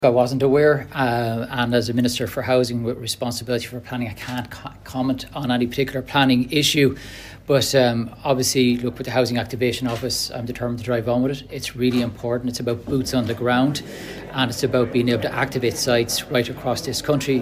Minister James Browne responded to the report this afternoon.